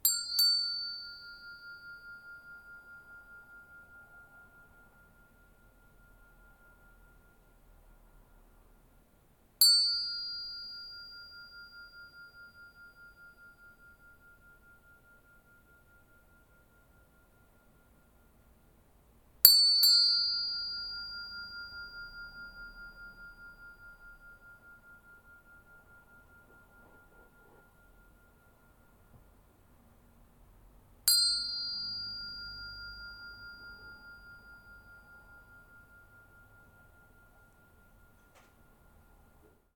TibetBell
bell chime ding Tibet sound effect free sound royalty free Sound Effects